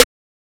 Neptune Snare.wav